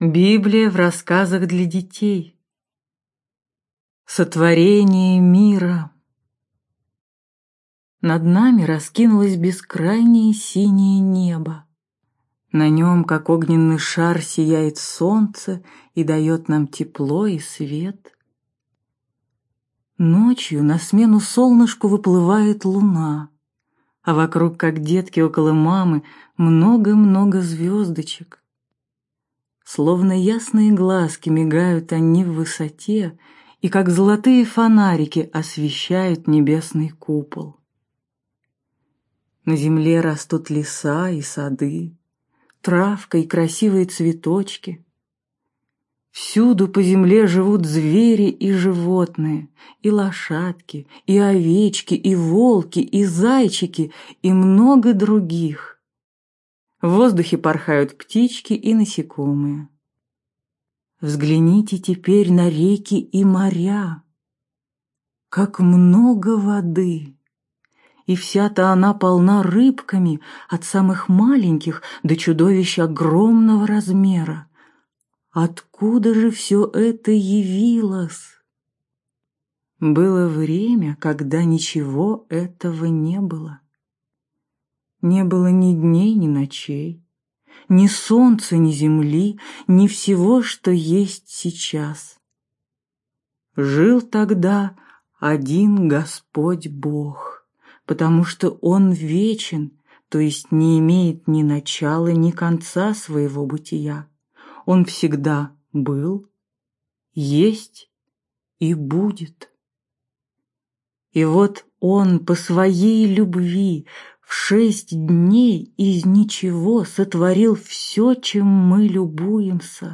Аудиокнига Библия в рассказах для детей | Библиотека аудиокниг